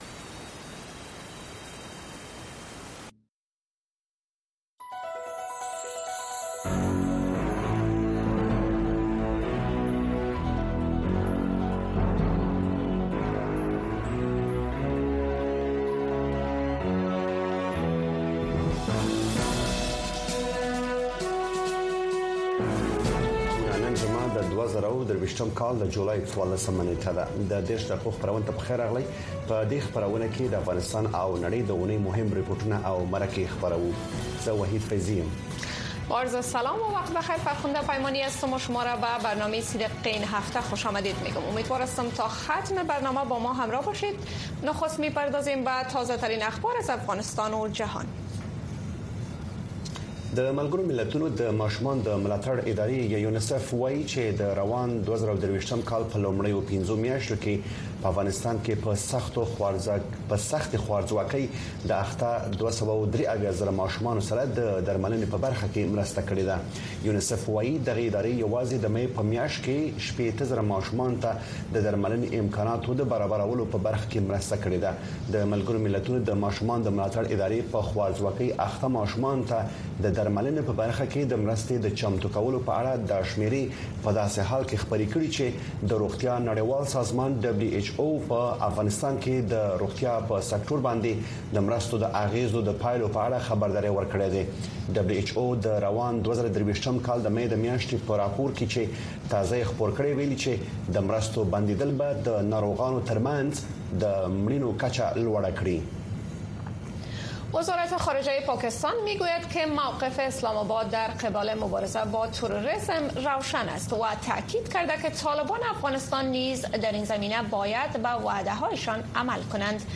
د ۳۰ دقیقې په اونیزه خپرونه کې د اونۍ مهمو پیښو، رپوټونو او مرکو ته بیاکتنه کیږي او د افغانستان د ورځنیو پیښو په اړه تازه او هر اړخیرې ارزونې وړاندې کیږي.